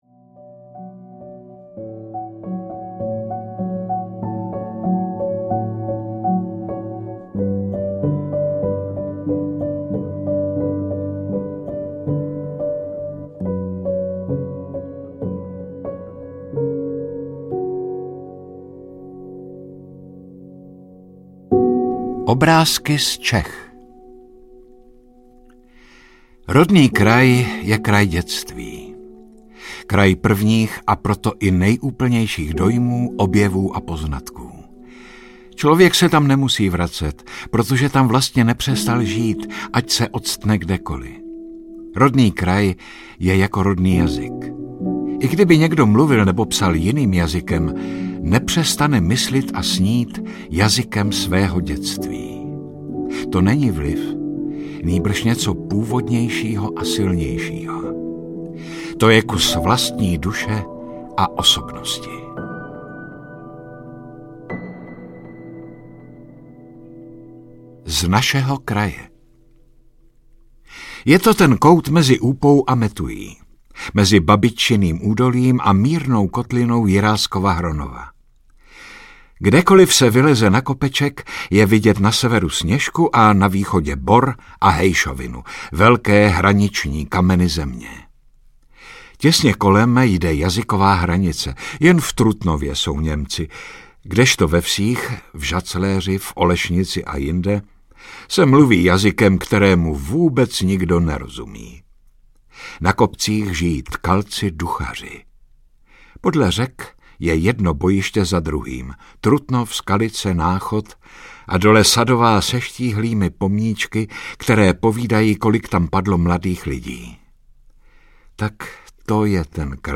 Obrázky z domova audiokniha
Ukázka z knihy
• InterpretJaromír Meduna
obrazky-z-domova-audiokniha